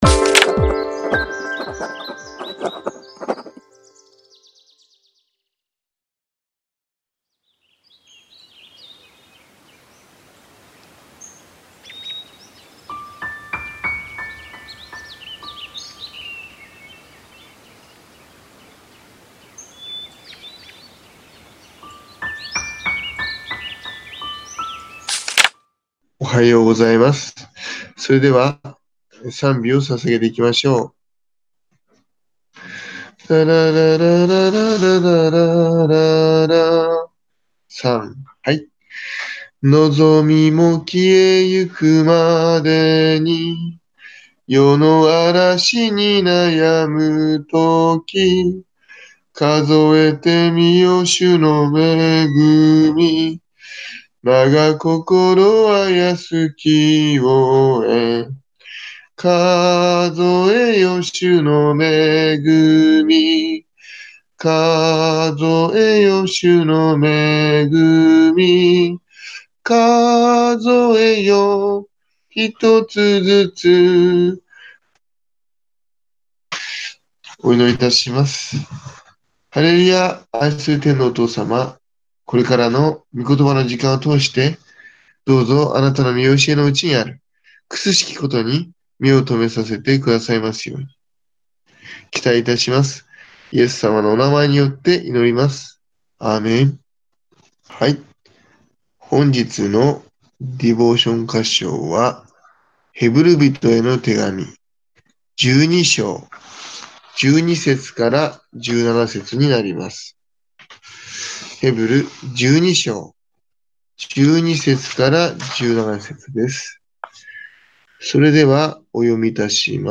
音声版（mp3)は右クリック「対象ファイルをダウンロード」で保存できます ※映像と音声が一部乱れている部分がございます。